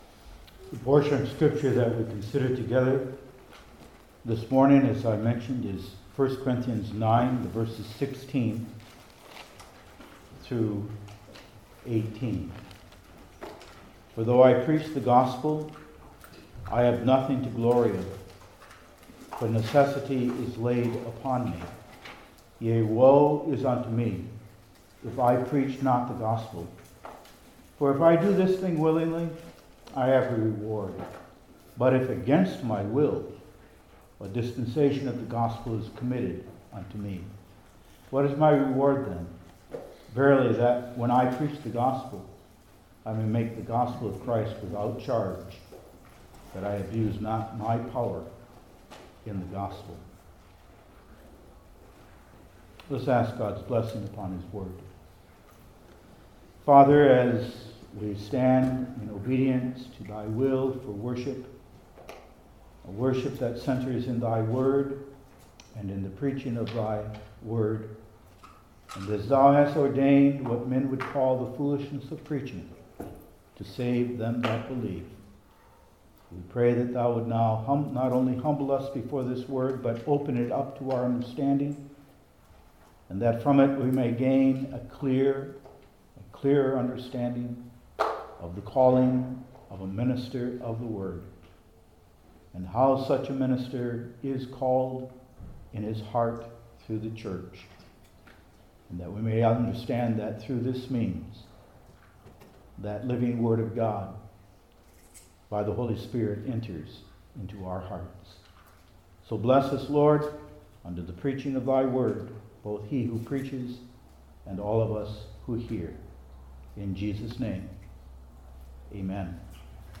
I Corinthians 9:16-18 Service Type: New Testament Individual Sermons I. His Sacred Task II.